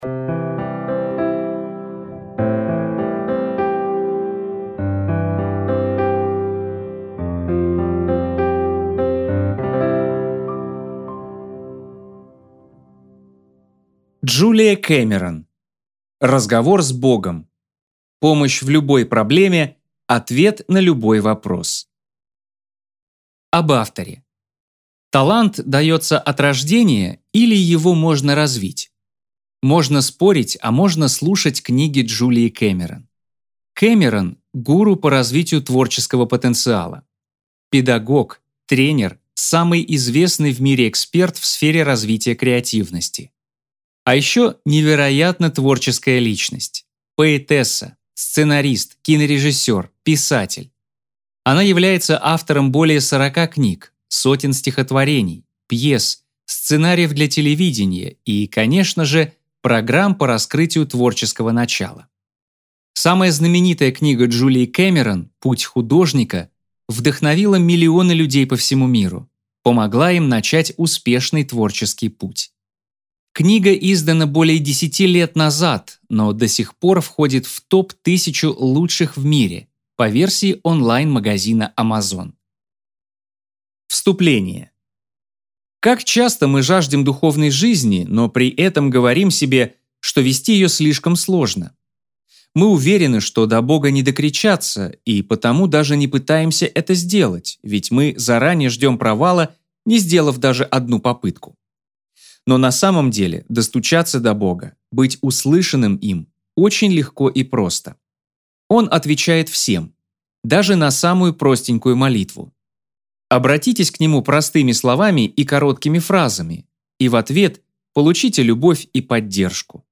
Аудиокнига Разговор с Богом. Помощь в любой проблеме, ответ на любой вопрос | Библиотека аудиокниг